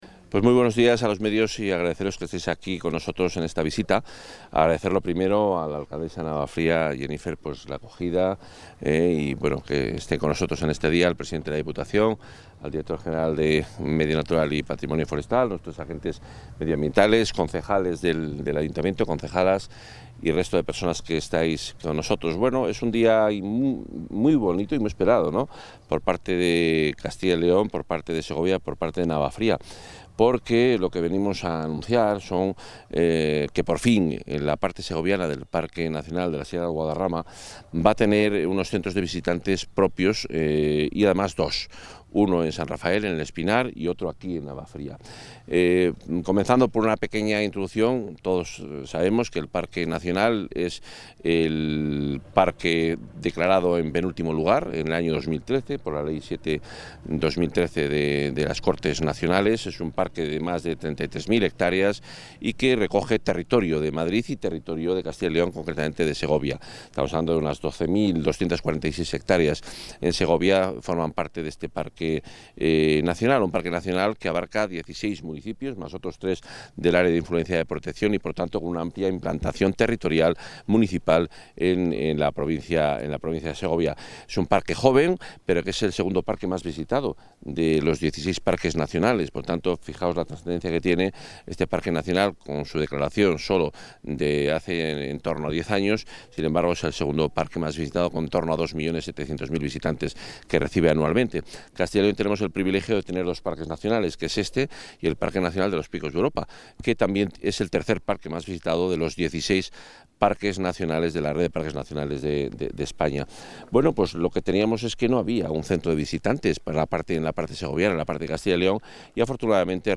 Intervención del consejero.
El consejero de Medio Ambiente, Vivienda y Ordenación del Territorio, Juan Carlos Suárez-Quiñones, ha visitado hoy la localidad de Navafría, donde se ubicará uno de los dos centros de visitantes con los que se dotará próximamente al Parque Nacional de la Sierra de Guadarrama, en la provincia de Segovia. Con una inversión de 2,2 millones de euros, la actuación será financiada con cargo a los fondos Next – Generation EU.